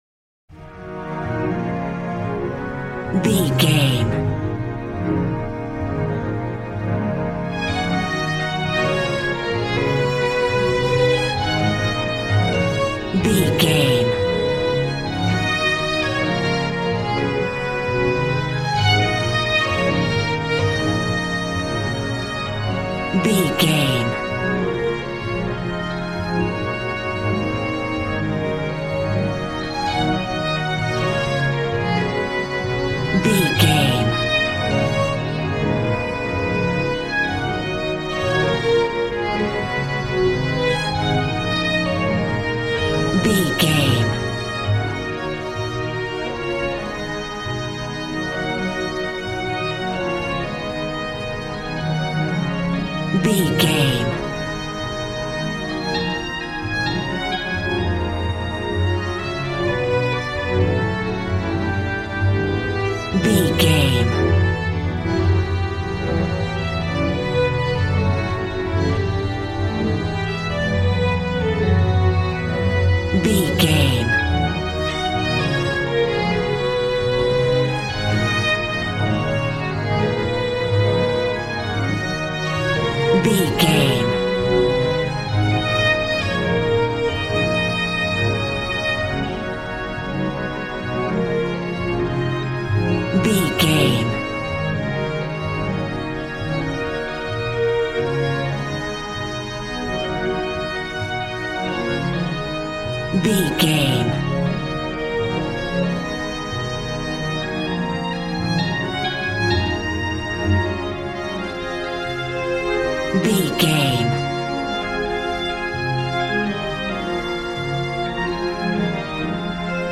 Aeolian/Minor
E♭
joyful
conga